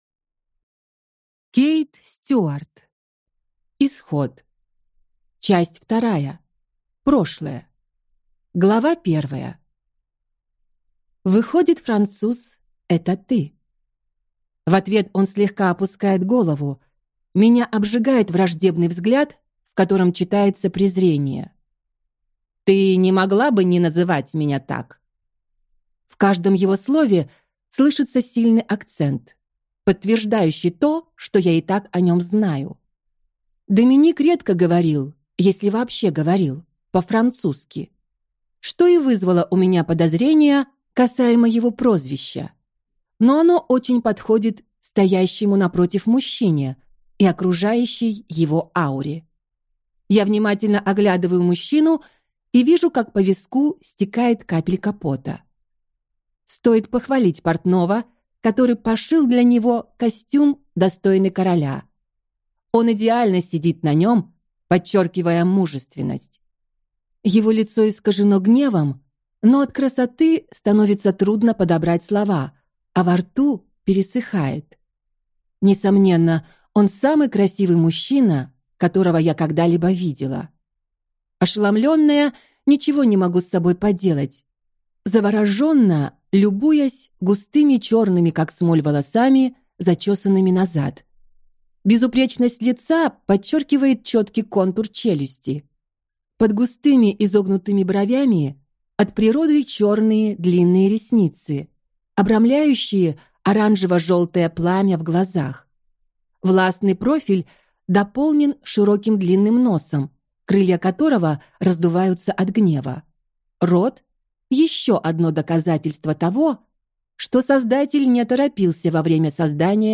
Аудиокнига Исход | Библиотека аудиокниг